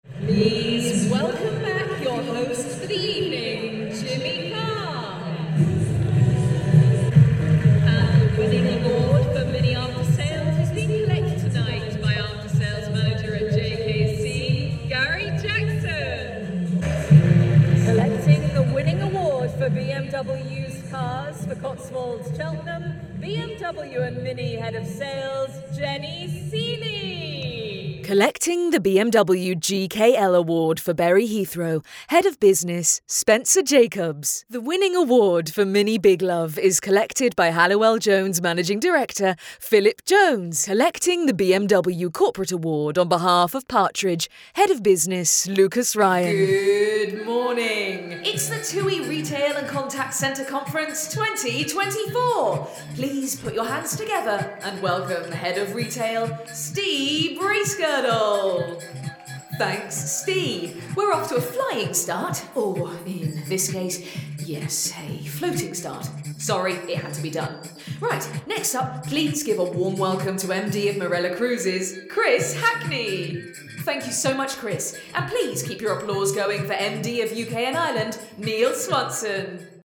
Professional Female Voice Over Talent & Actors | Affordable & Ready to Hire
I can bring the voice of a confident, witty, energetic girl next door. My voice can also evoke the engaging and articulate tone of an informed professional.